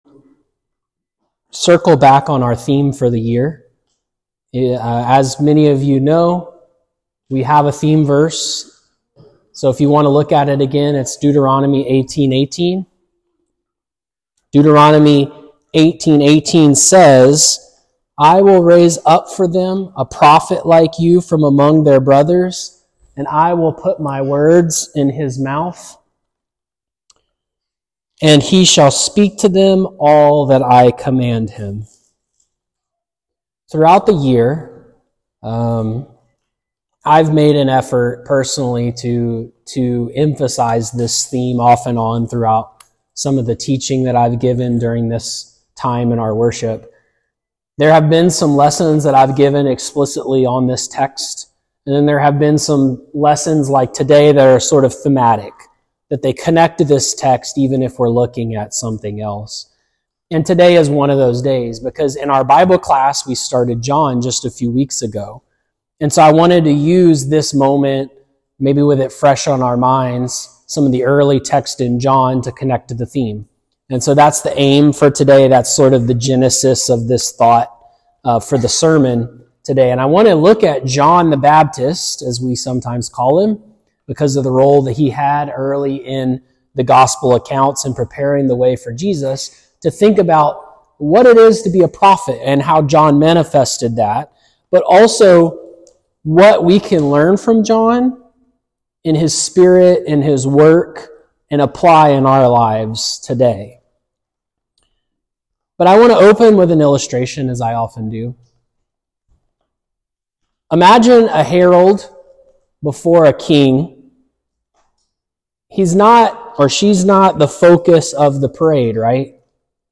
A lesson from our 2025 Annual theme sermon series that uses moments in the lives of God’s prophets to explore the meaning, fulfillment, and application of Deuteronomy 18.18.